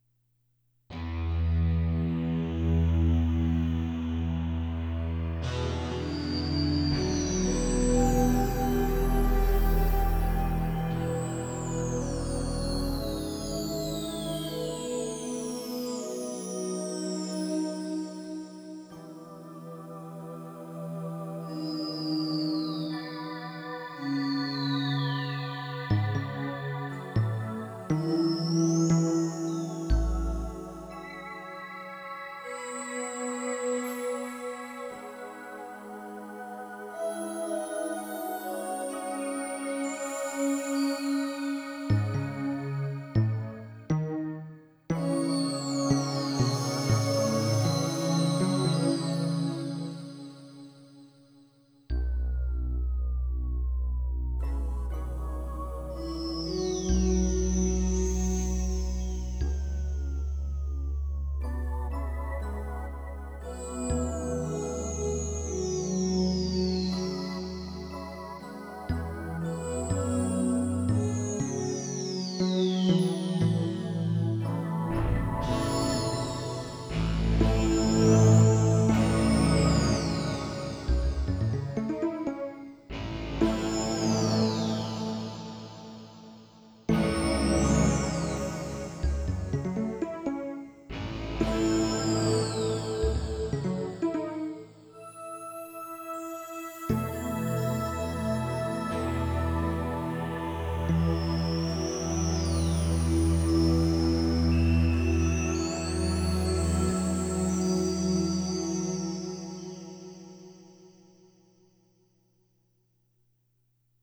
Sound Effects Three Synthesizers